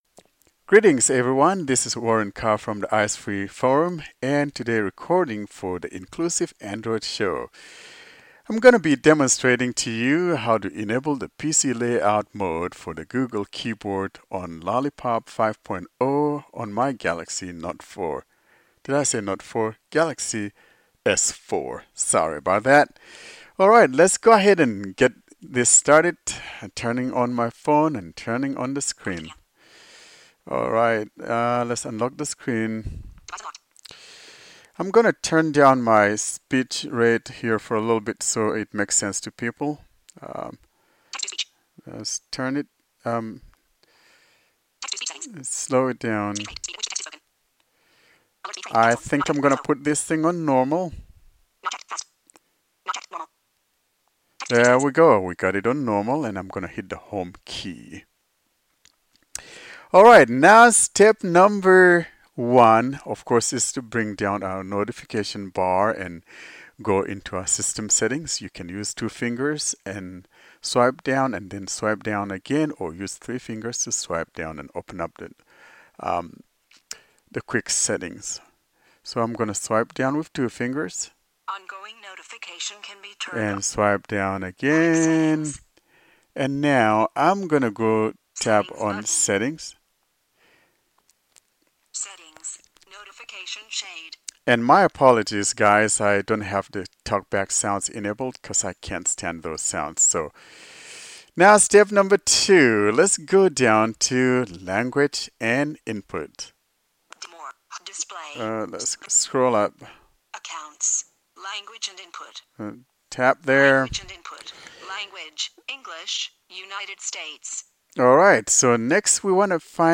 This audio tutorials shows you how to enable the PC layout, on your Google Keyboard, running Lollipop 5.x.